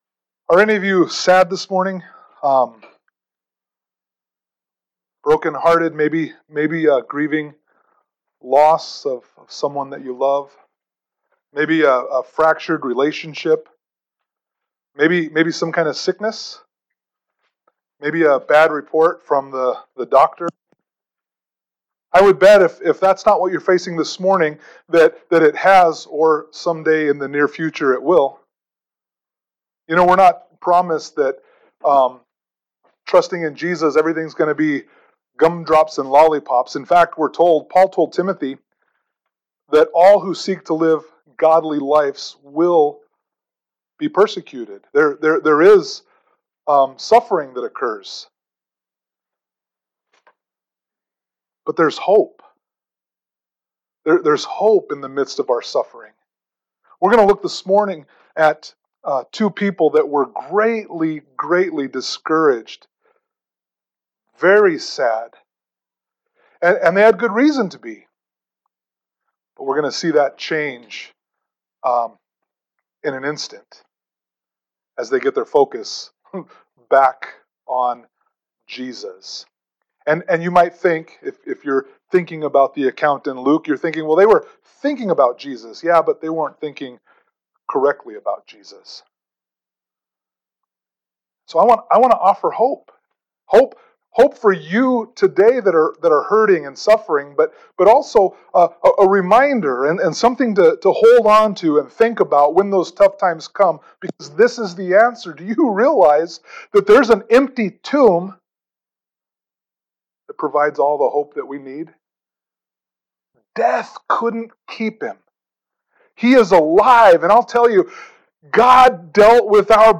Easter Service 2021 – Luke 24
Passage: Luke 24 Service Type: Holiday Service